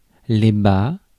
Ääntäminen
France (Paris): IPA: [le ba]